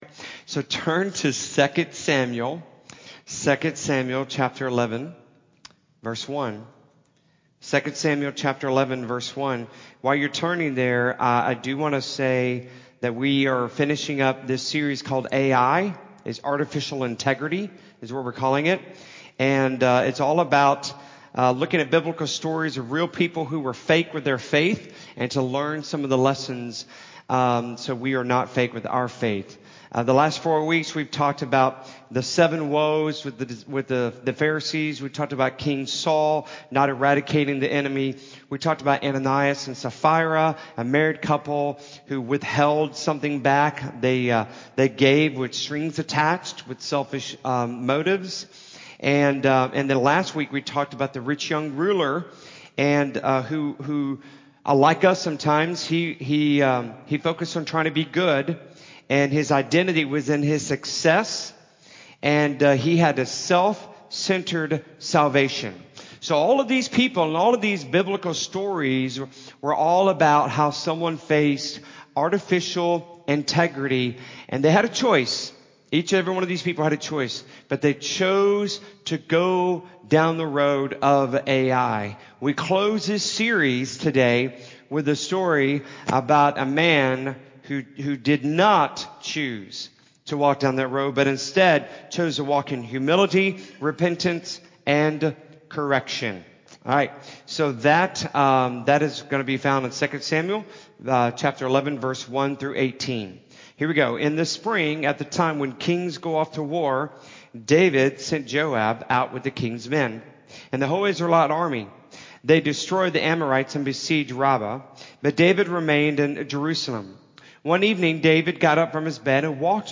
You-Are-That-Man-Sermon-Audio-1-CD.mp3